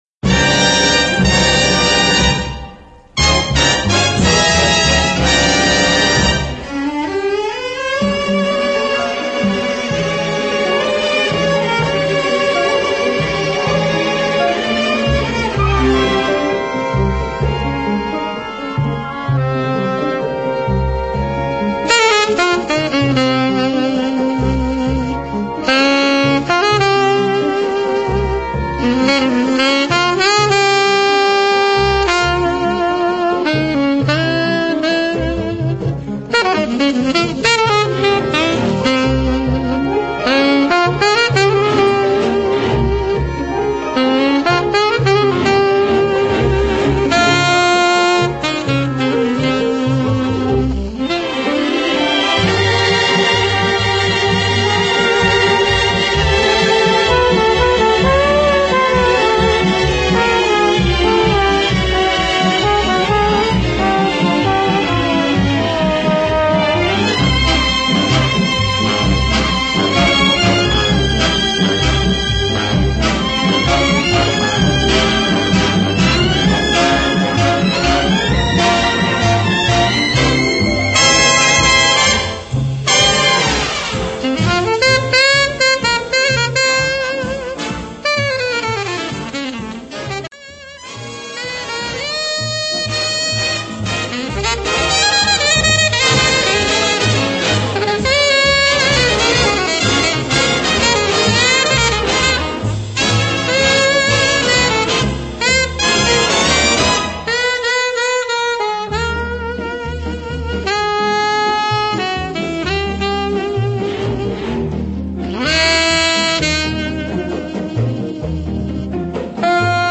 Voicing: Alto Saxophone